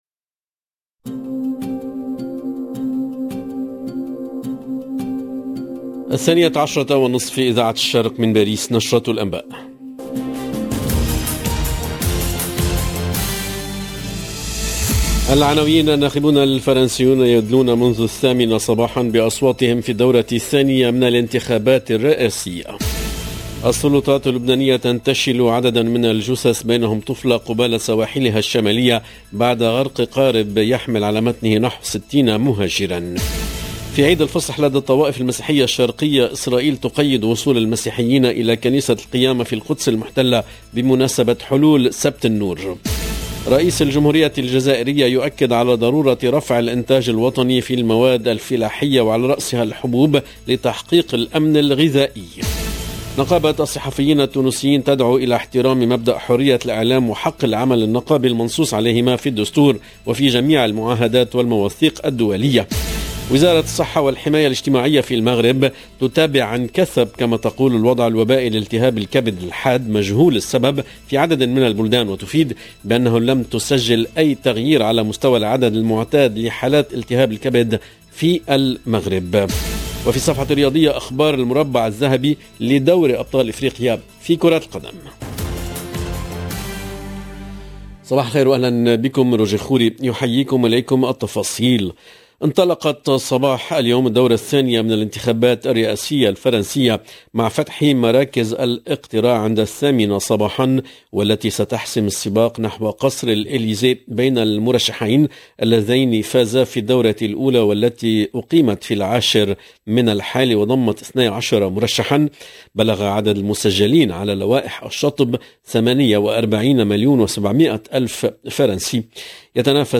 LE JOURNAL DE MIDI 30 EN LANGUE ARABE DU 24/04/22